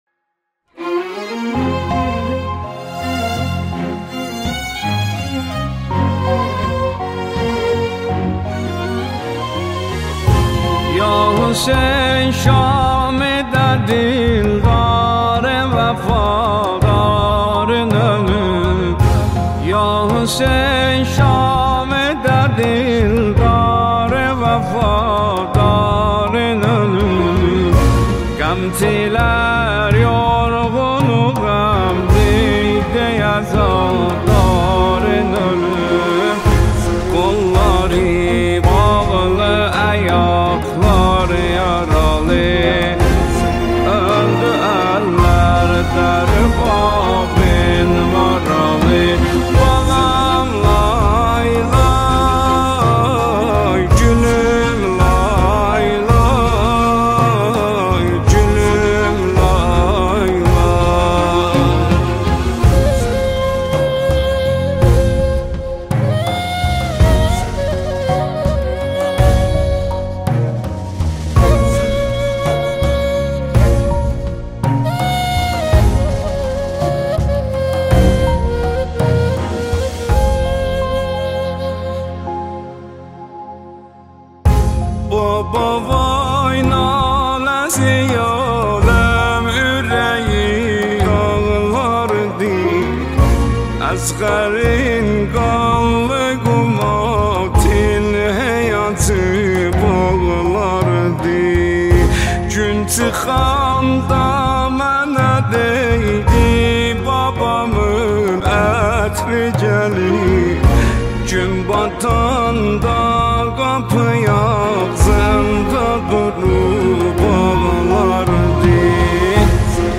مداحی ترکی